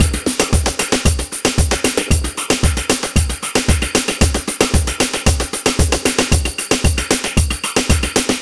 Ala Brzl 3 Drmz Wet 1b.wav